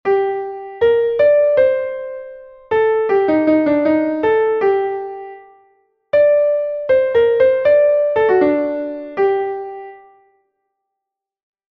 No caso de que a equivalencia sexa entre un compás de subdivisión ternaria e outro binaria, ao executalo pareceravos que hai un cambio de tempo ou velocidade, pero non é así.